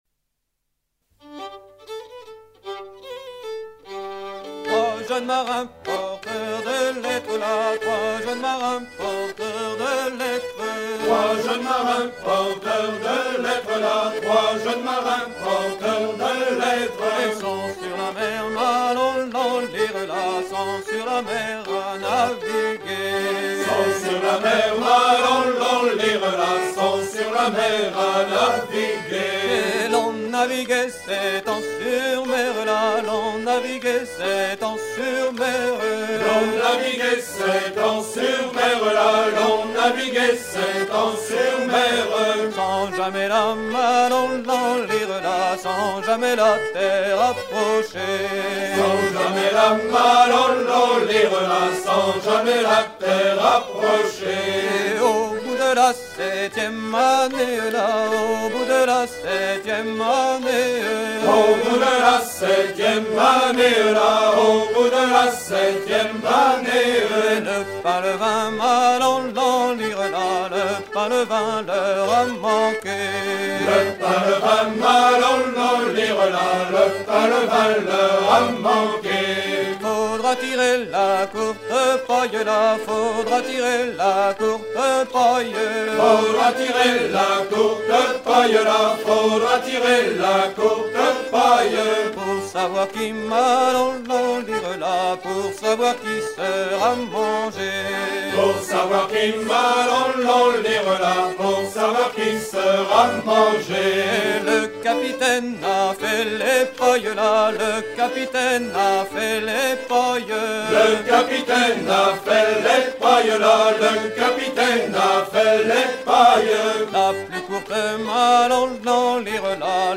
version recueillie en presqu'îled Rhuys où elle sert à danser le tour ou en dro en breton
danse : tour (Bretagne)
Pièce musicale éditée